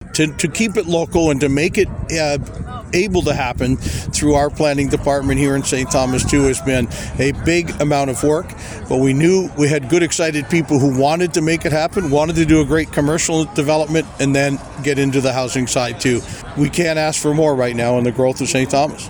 That was the observation of Mayor Joe Preston on Wednesday morning for the groundbreaking ceremony at the 14-storey Highlands Tower 1 apartment building .